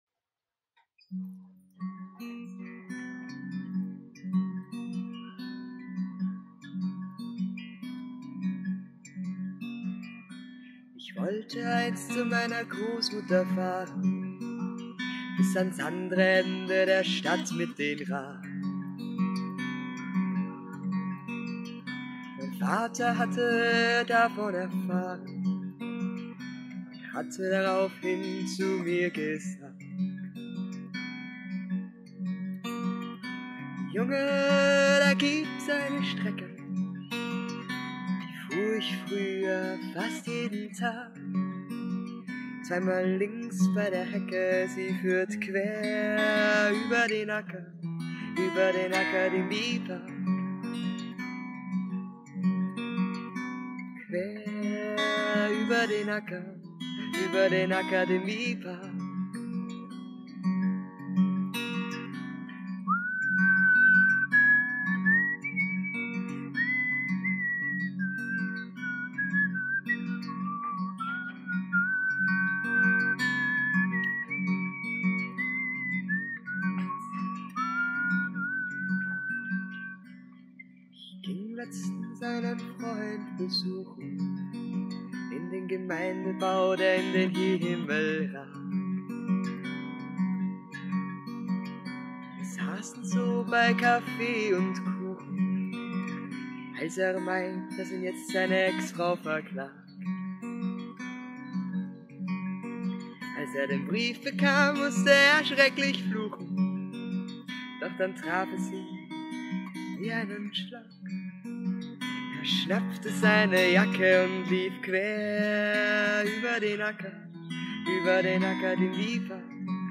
Es wurde beim „Fest für den Akadmiepark“ live aufgenommen.